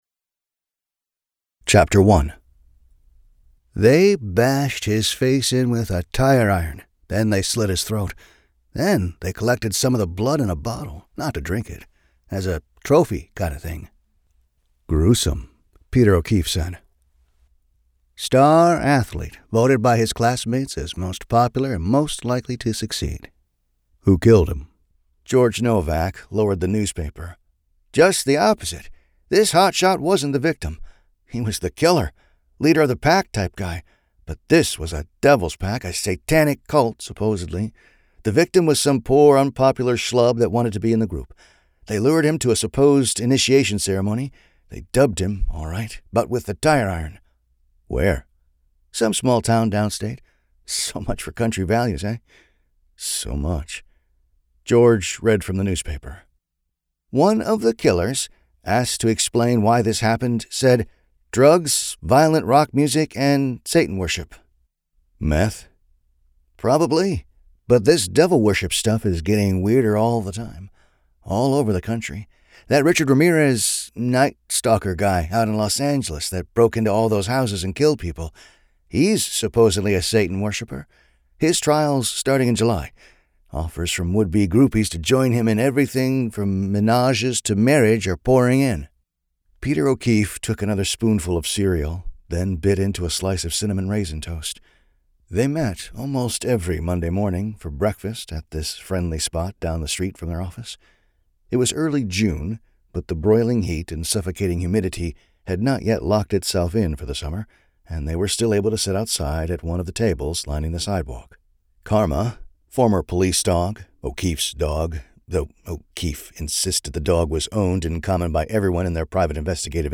• Audiobook • 12 hrs, 22 mins